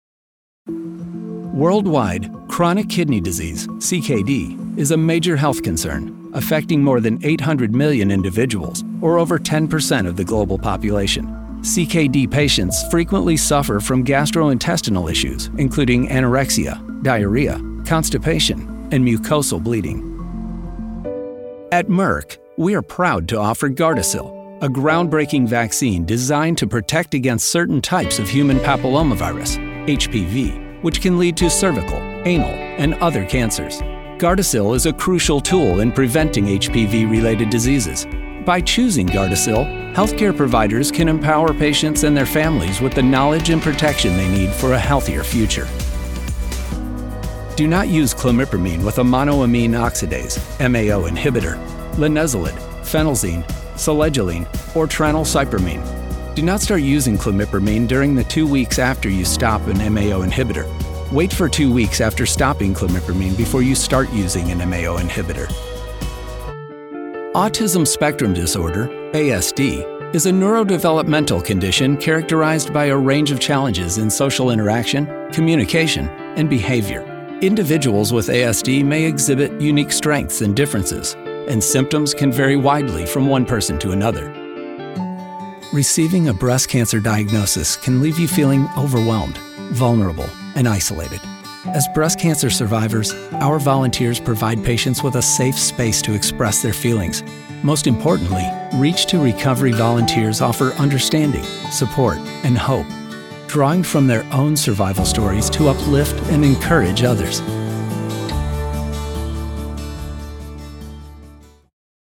American Male Voice Talent - Friendly, Relatable, Confident, Authentic, Real
Middle Aged
Medical Narration